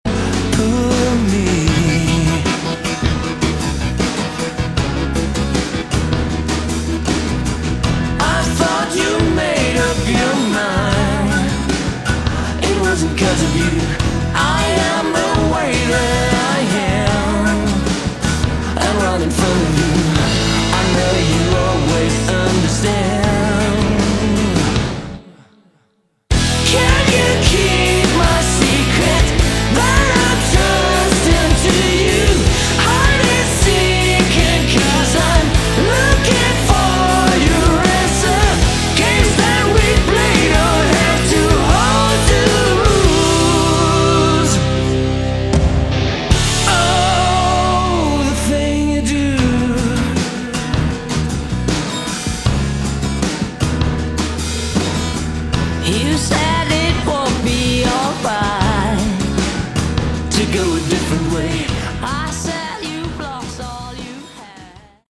Category: AOR
lead and backing vocals
guitar, backing vocals, keyboards, bass
drums, backing vocals
lapsteel